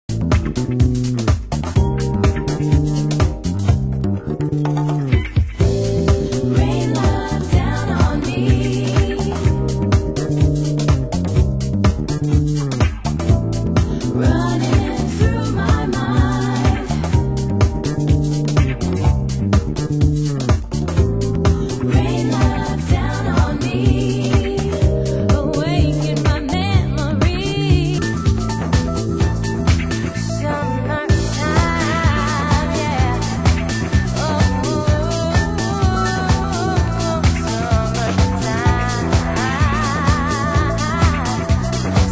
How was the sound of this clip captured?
Format: Vinyl 12 Inch